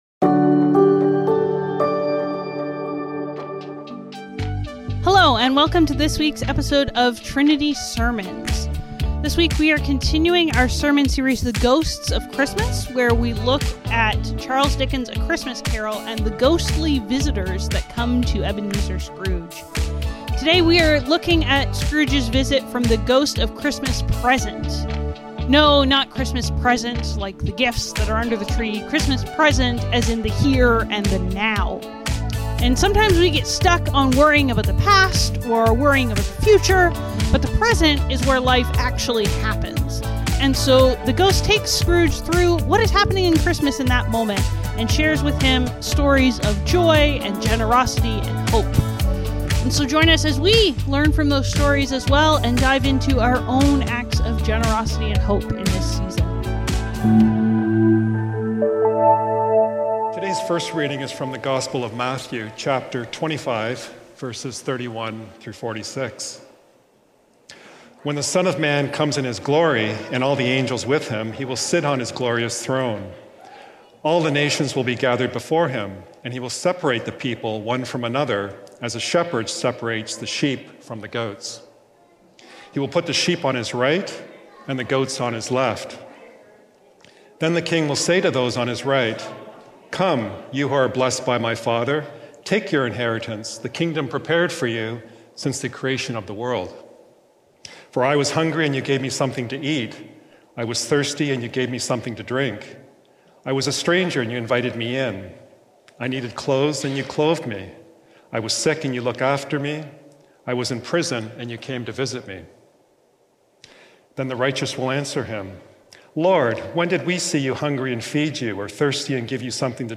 Trinity Streetsville - Presence | The Ghosts of Christmas | Trinity Sermons